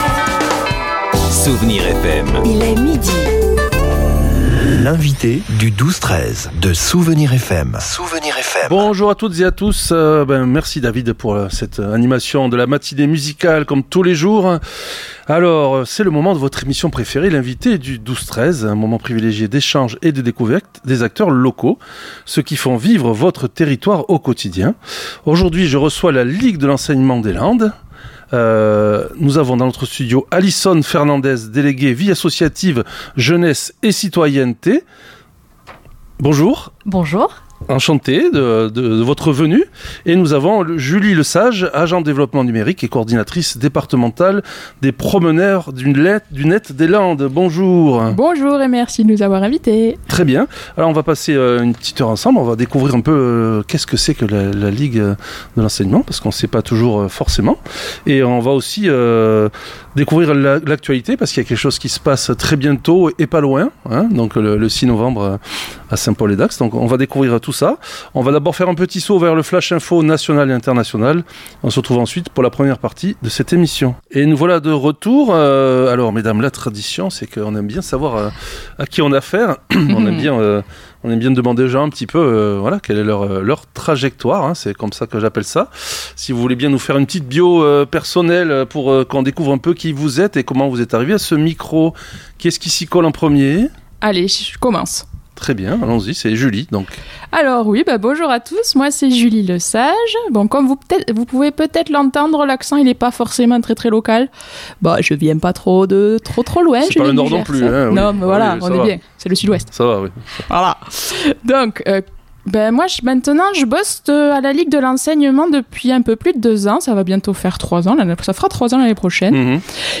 L'invité(e) du 12-13 recevait aujourd'hui LA LIGUE DE L’ENSEIGNEMENT DES LANDES.